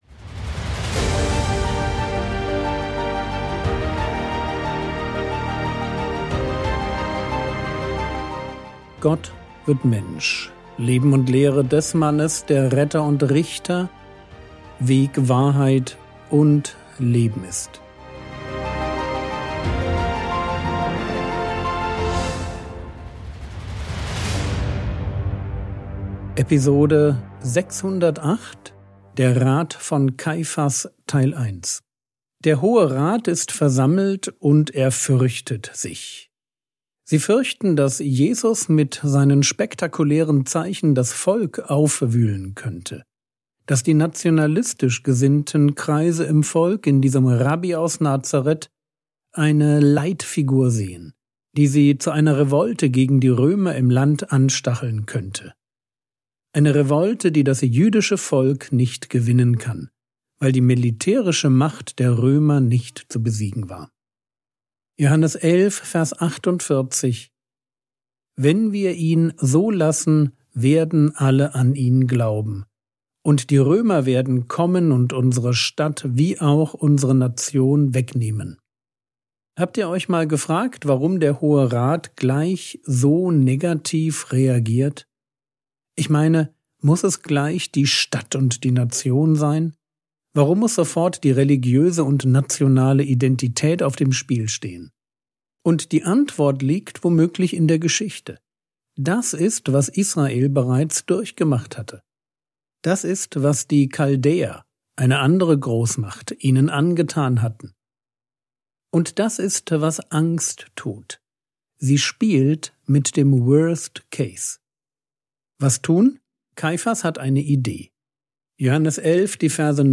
Episode 608 | Jesu Leben und Lehre ~ Frogwords Mini-Predigt Podcast